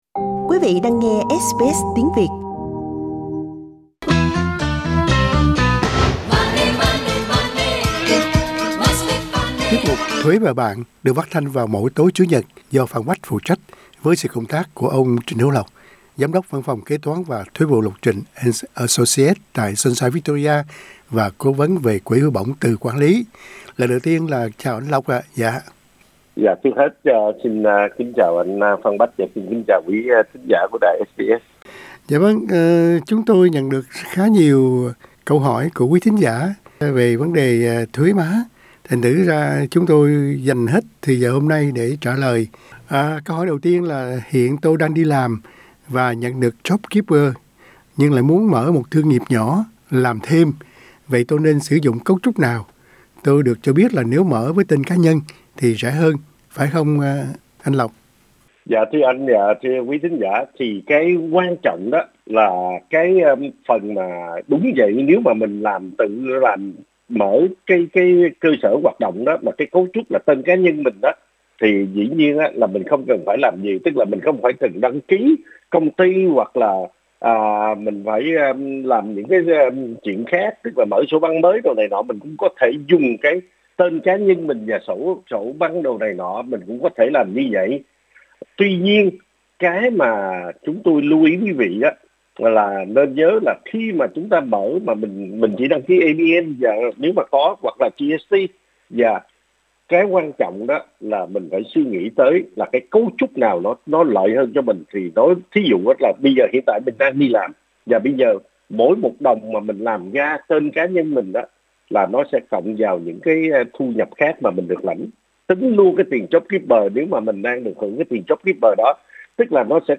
Chúng tôi dành trọn thời lượng phát thanh để trả lời các câu hỏi của thính giả gởi đến.